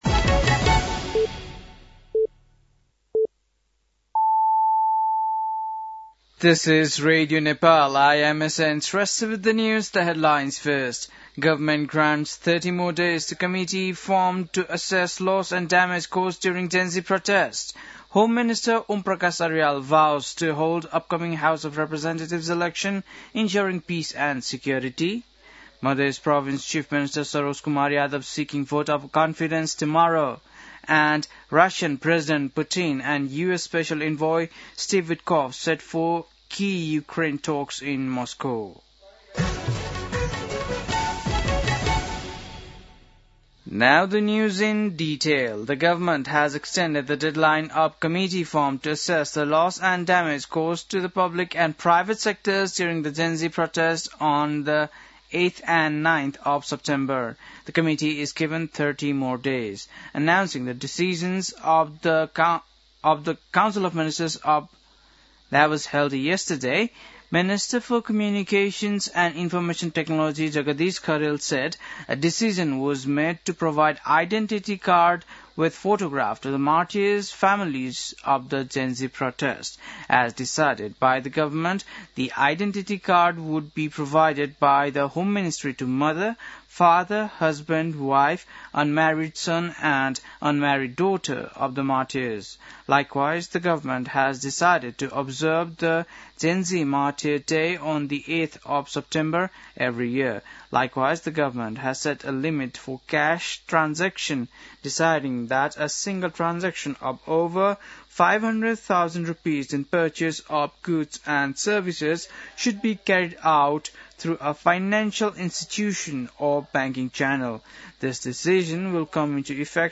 बेलुकी ८ बजेको अङ्ग्रेजी समाचार : १६ मंसिर , २०८२